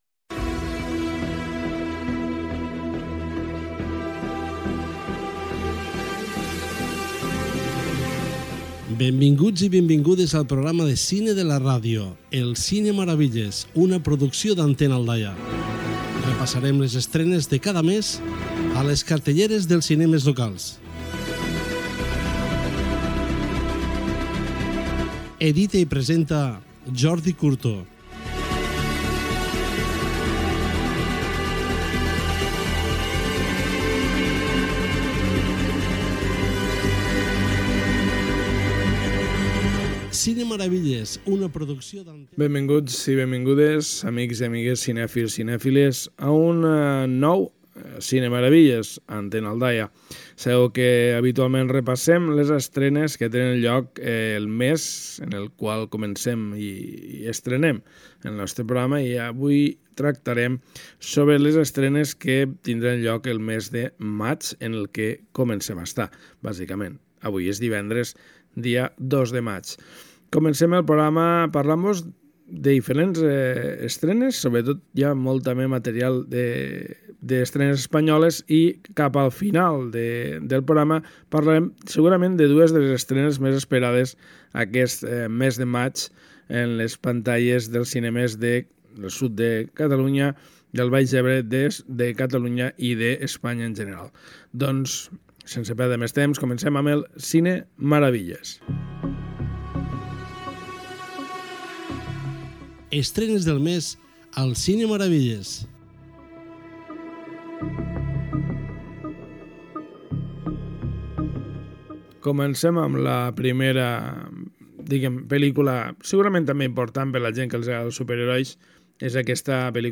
90c4de5dbd904daddad272873bda7adbe99d83b6.mp3 Títol Antena Aldaia Emissora Antena Aldaia Titularitat Pública municipal Nom programa Cine Maravilles Descripció Careta i sumari del programa.